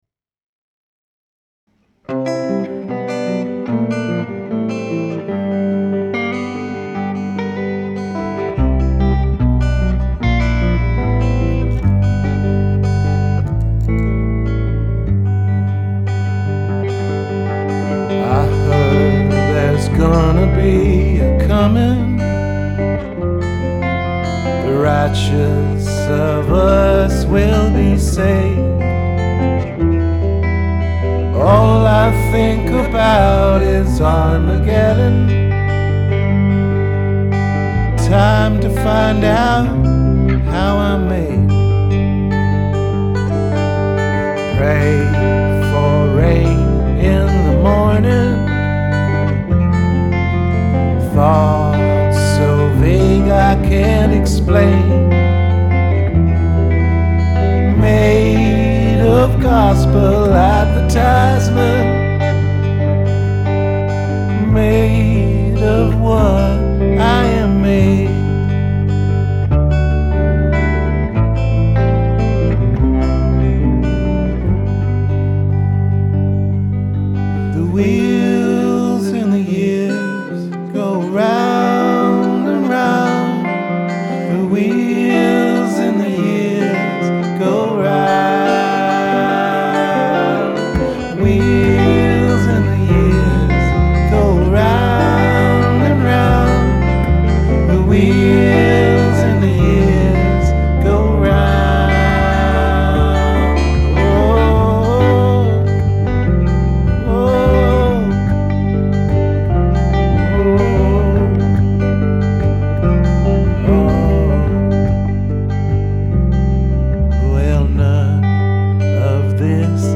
Rehearsals 13.3.2012